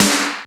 HR16B SNR 02.wav